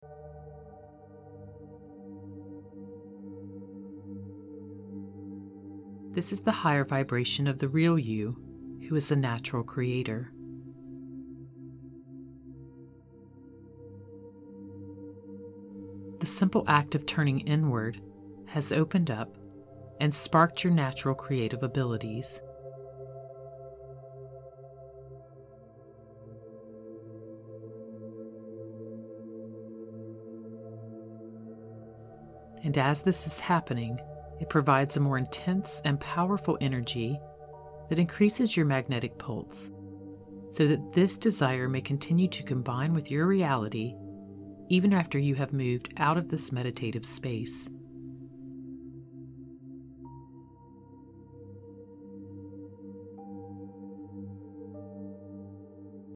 Magnetize What You Want from the Quantum Field – Powerful Guided Meditation
The meditation music has been tuned to 528Hz, known as the “Miracle Tone” to assist you in manifesting miracles and promoting a calm and confident state.